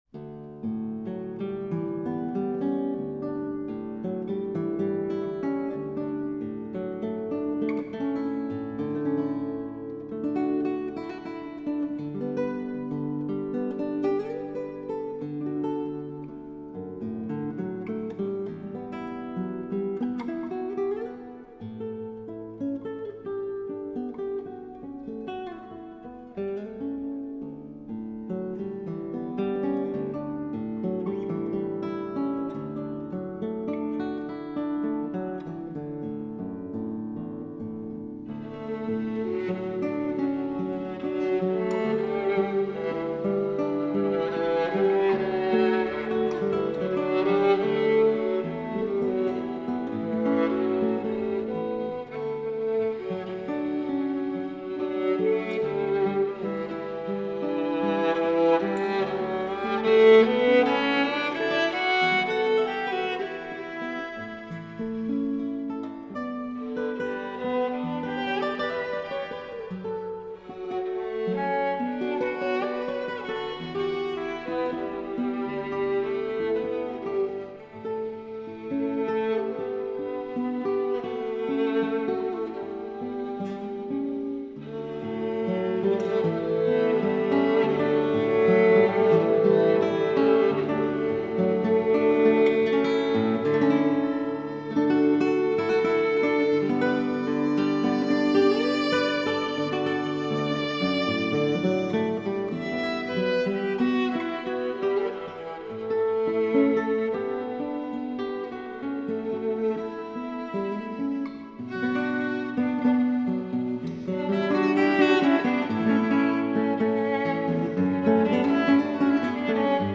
Duo Bratsche & siebensaitige Gitarre,